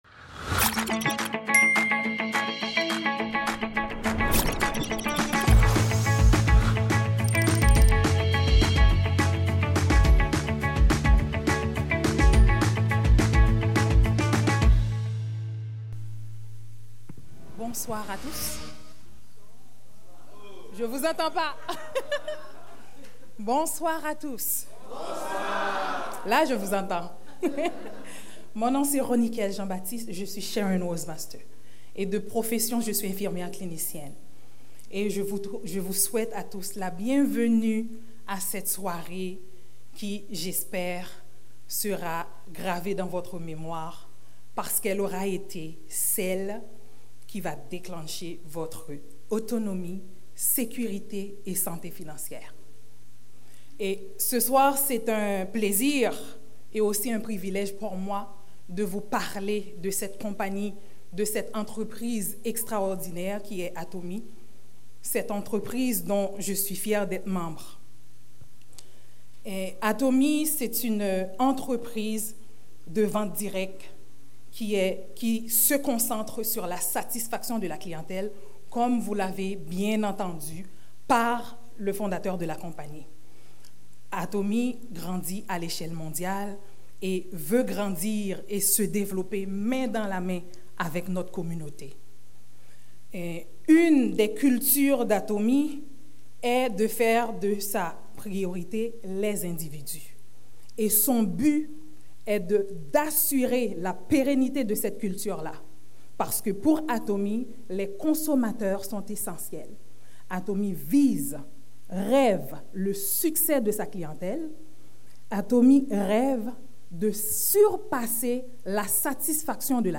Montreal Seminar